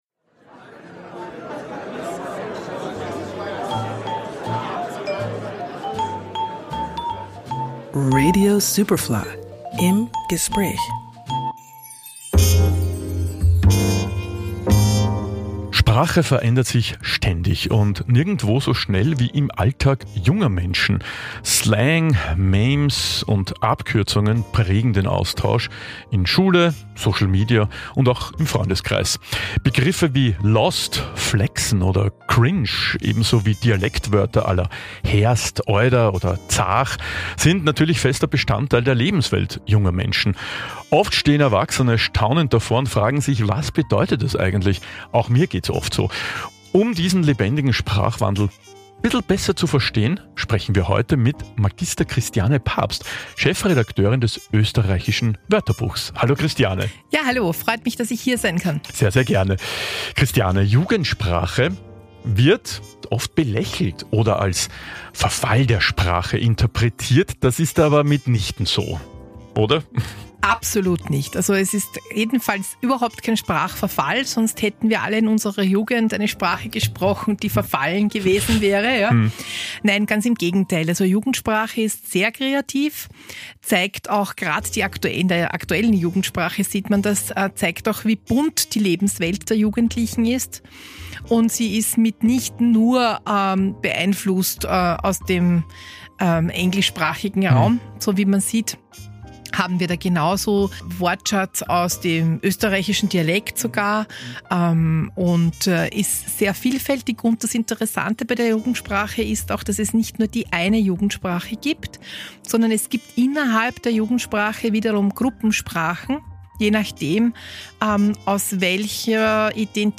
Superfly Interviews